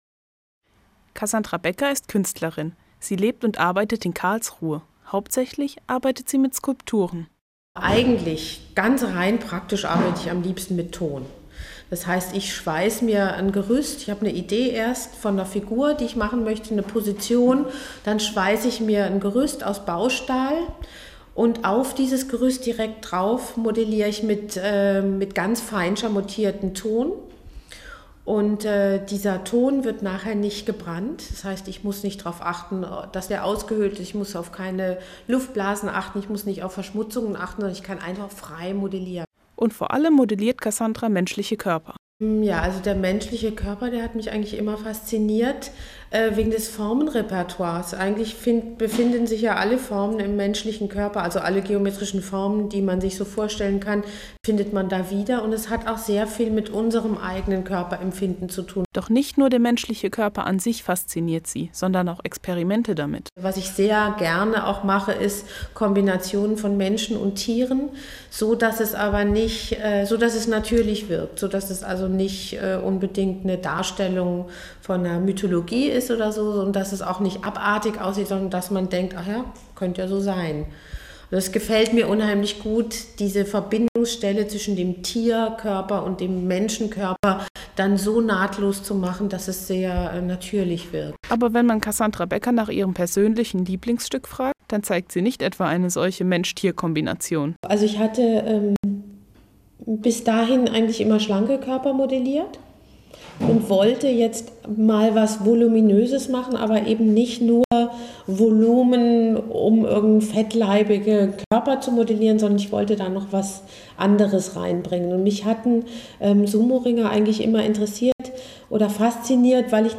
Im Interview hat sie mir verraten, mit welchem Medium sie am Liebsten arbeitet und was sie dazu bewegt hat, Sumoringer zu modellieren.